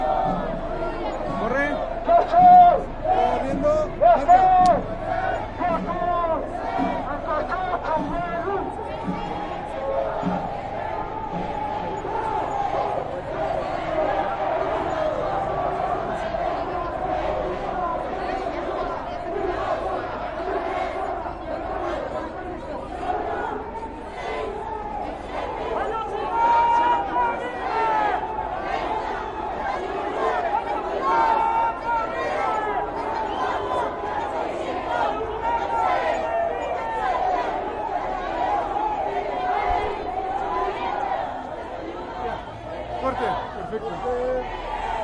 描述：1968年，为了纪念被杀害的学生，墨西哥的一群暴徒......街道，人群，学生，人，墨西哥，西班牙语的一切
Tag: 人群 抗议 暴民